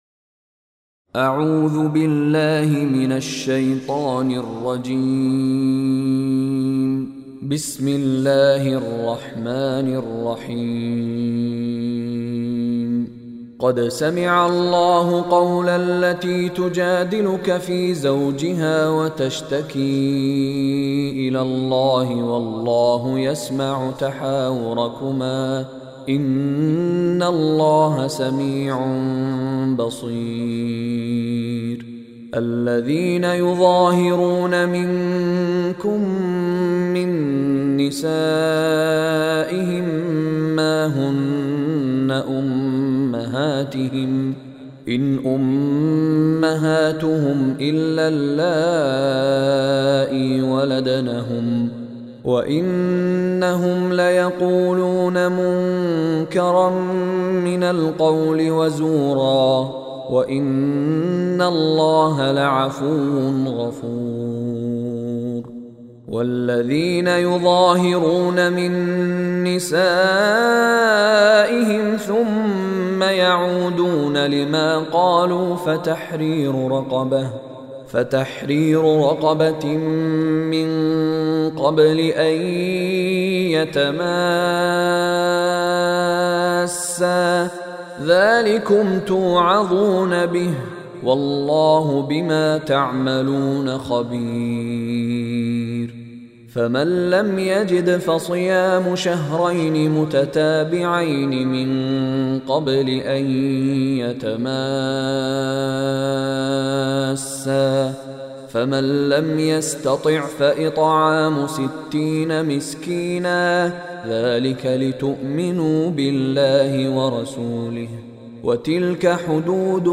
Surah Al-Mujadila Recitation by Mishary Rashid
Surah Al-Mujadila is 58th chapter / Surah of Holy Quran. It has 22 verses or ayaats. Listen online and download mp3 recitation / tilawat in arabic of Surah Al-Mujadila in the beautiful voice of Sheikh Mishary Rashid Alafasy.